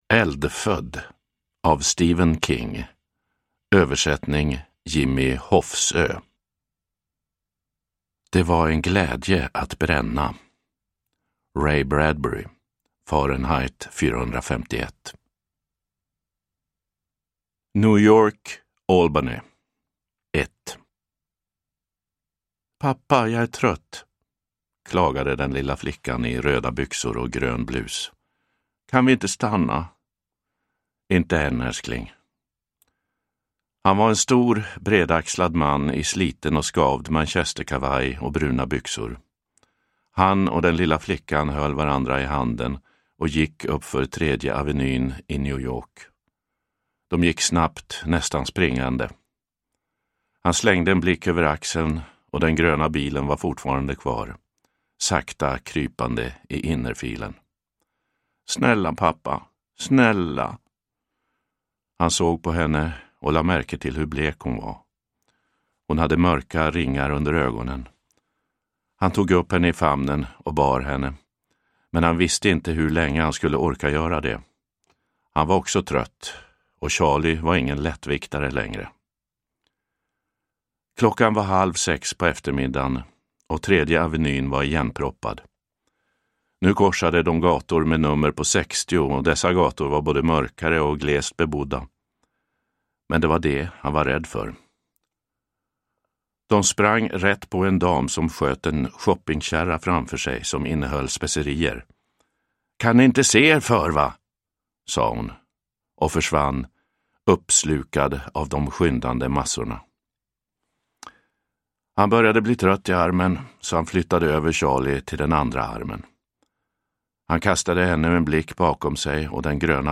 Eldfödd – Ljudbok – Laddas ner
Uppläsare: Lennart Jähkel